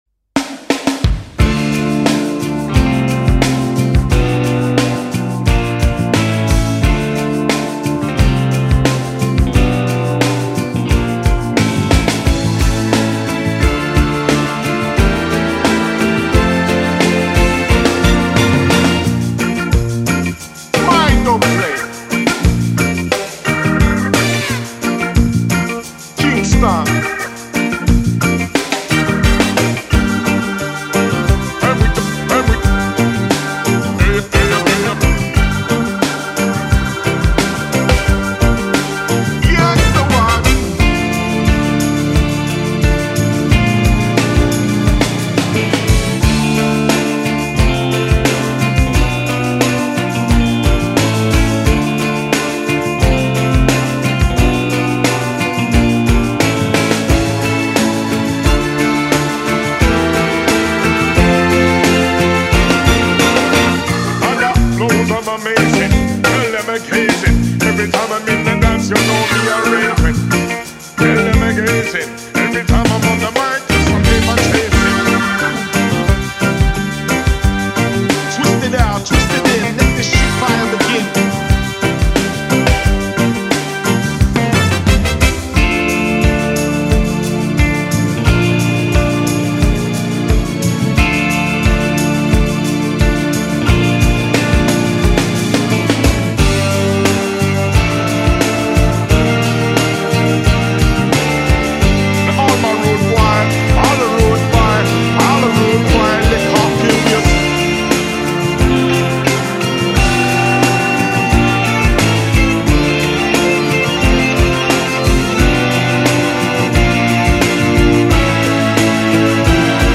aerien - nostalgique - calme - guitare electrique - dobro